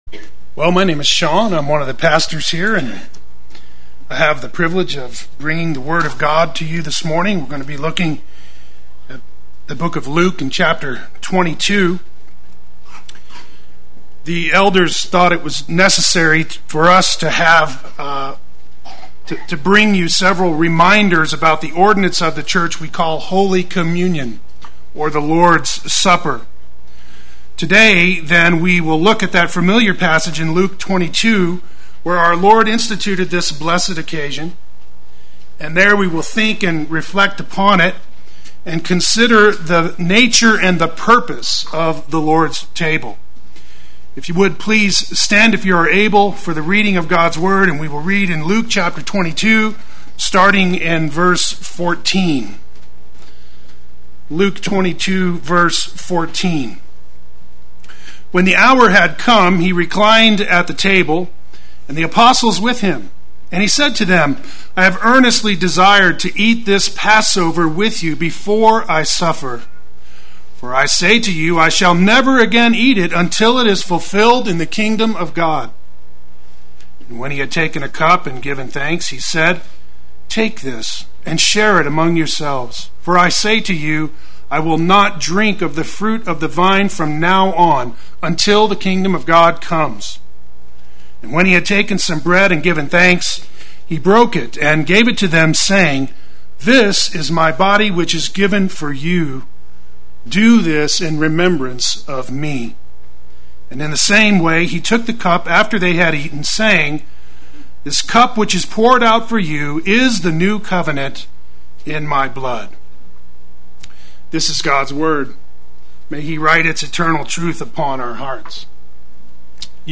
Play Sermon Get HCF Teaching Automatically.
In Remembrance of Me Sunday Worship